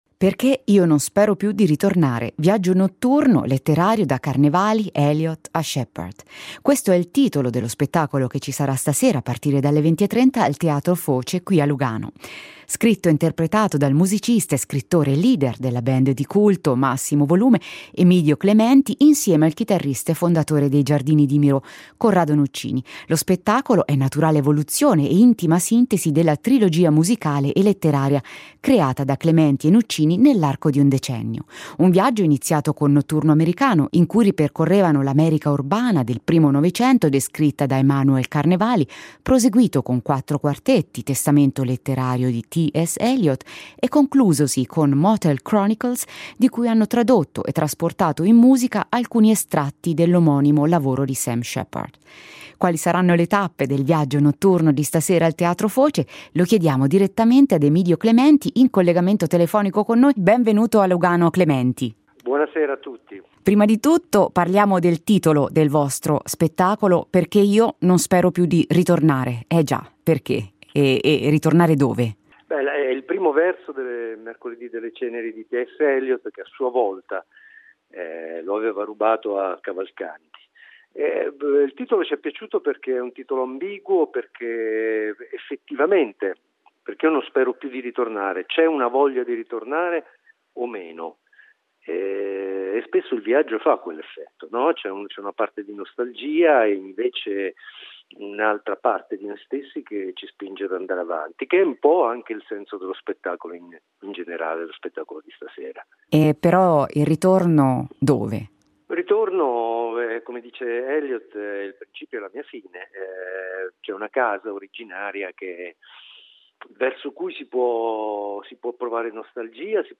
Ne abbiamo parlato in Ho Visto Cose con Emidio Clementi occasione dello spettacolo al Teatro Foce il 04 dicembre.